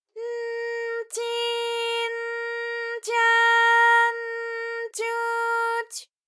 ALYS-DB-001-JPN - First Japanese UTAU vocal library of ALYS.
ty_n_tyi_n_tya_n_tyu_ty.wav